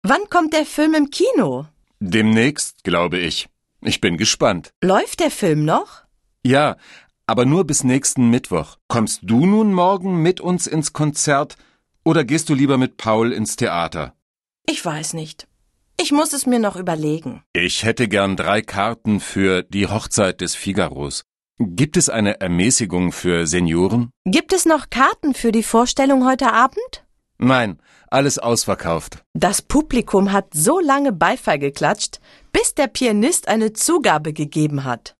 Un peu de conversation - Les sorties